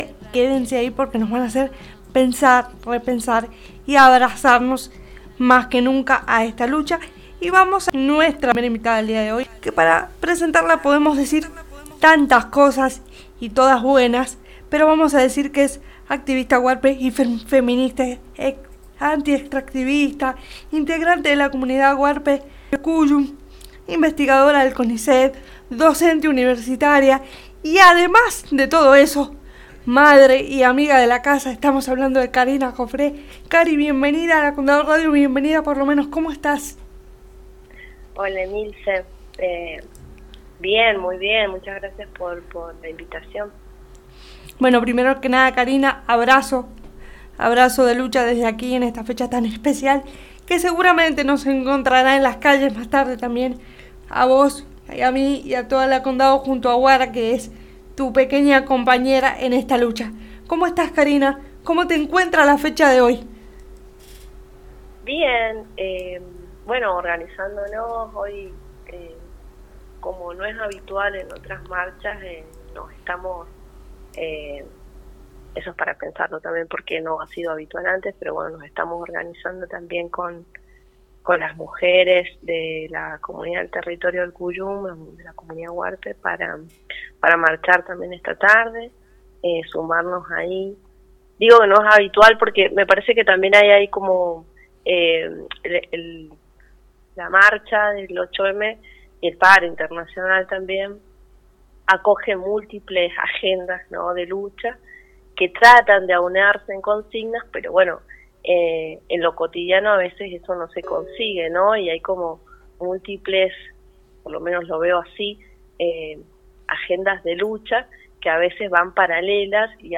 Desde la «Condado Radio» se preparo un programacion especial para acompañar la lucha de las mujeres y diversidades.